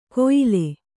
♪ koyile